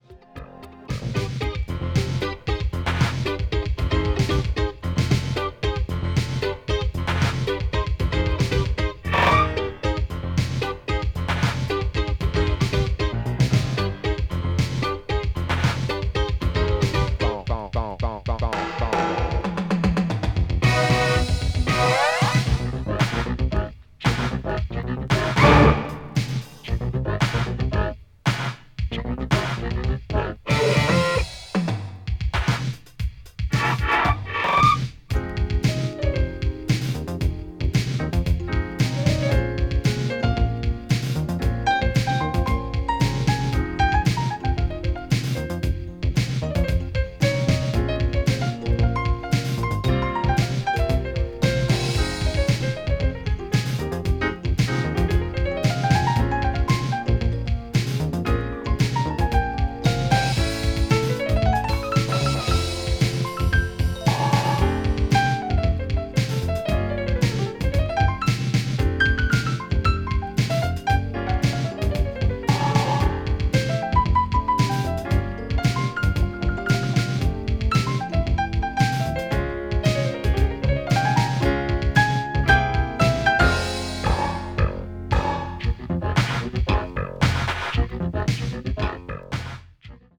media : EX/EX(わずかにチリノイズが入る箇所あり)
electro   leftfiled   new wave   obscure dance   synth pop